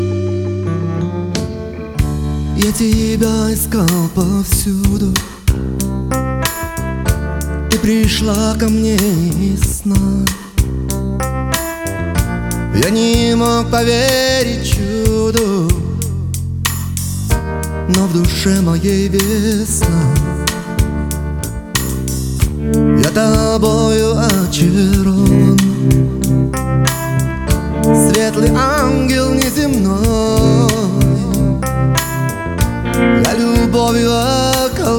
Жанр: Русская поп-музыка / Рок / Русский рок / Русские